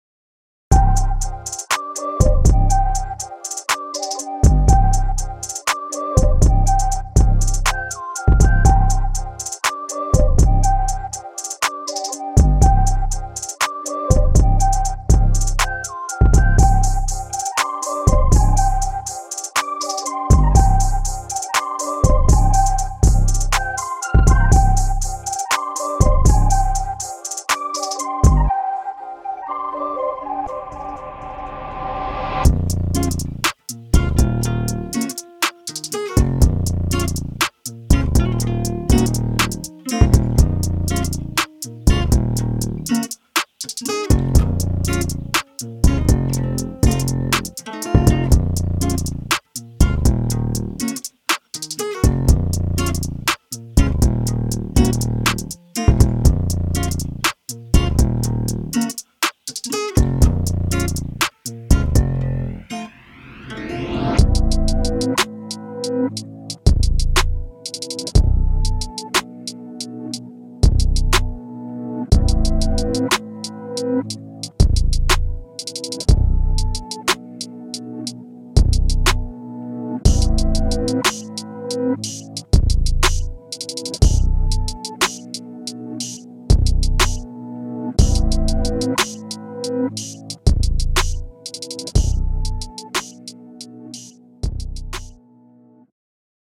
提供了另一剂新鲜的Trap风格音乐旋律
音色试听
电音采样包